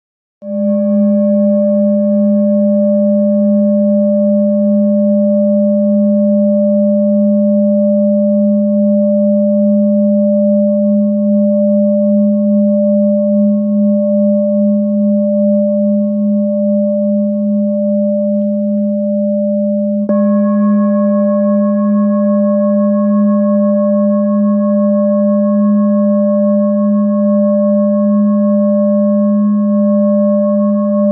High Quality Peter Hess Singing Bowls, Hand Hammered Clean Finishing M2 , A bowl used for meditation and healing, producing a soothing sound that promotes relaxation and mindfulness
Singing Bowl Ching Lu Kyogaku
Material 7 Metal Bronze